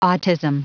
উচ্চারণ: ইংরেজি উচ্চারণ অটিজ ˈɔːtɪz(ə)m
শ্রবণ নমুনা>বাংলাতে এই শব্দটি উচ্চারিত হয় 'অটিজম্' [ɔ.ʈi.ɟɔm] হিসেবে।